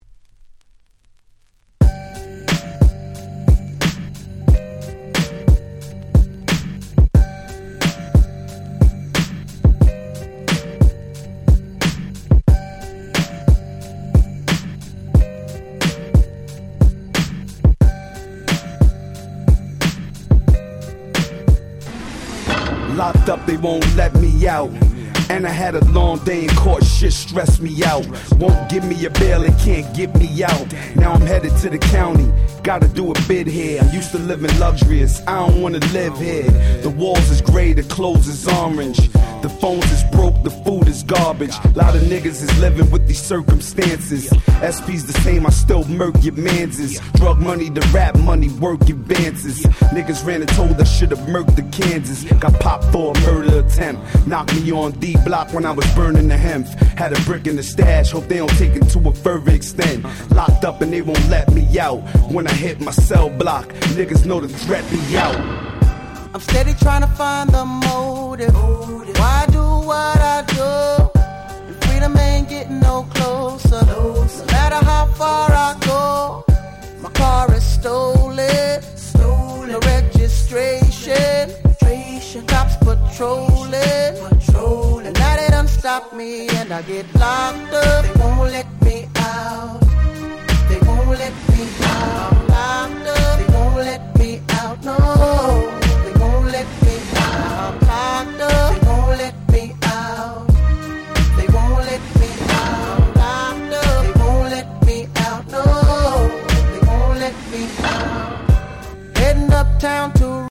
03' Super Hit R&B !!
それくらい普通にHip Hopな感じの楽曲です。